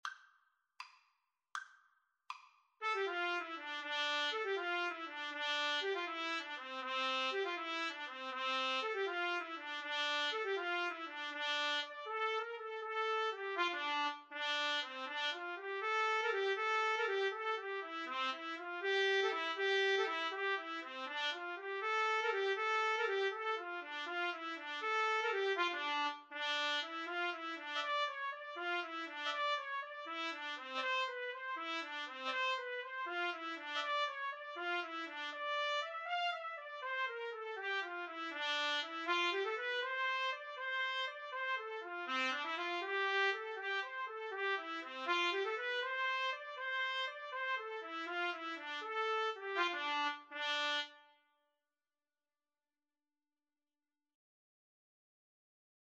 D minor (Sounding Pitch) E minor (Trumpet in Bb) (View more D minor Music for Trumpet-Trombone Duet )